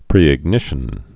(prēĭg-nĭshən)